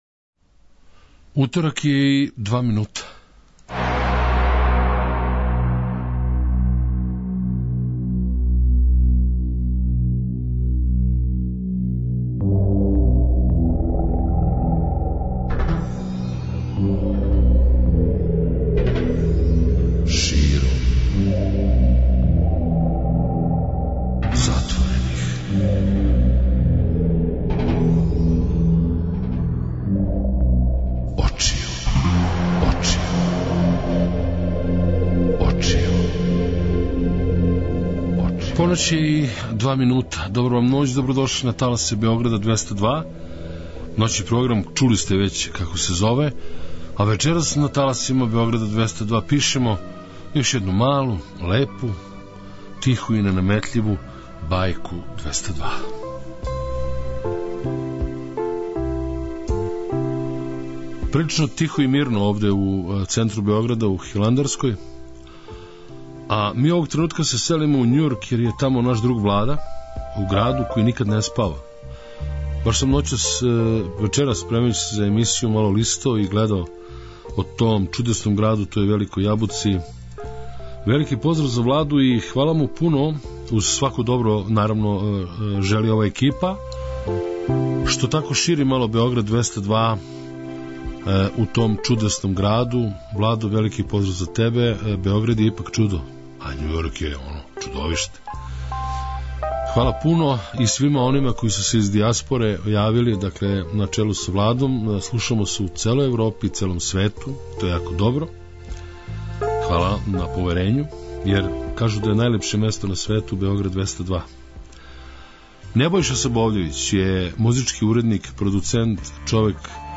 преузми : 56.33 MB Широм затворених очију Autor: Београд 202 Ноћни програм Београда 202 [ детаљније ] Све епизоде серијала Београд 202 Блузологија Свака песма носи своју причу Летње кулирање Осамдесете заувек!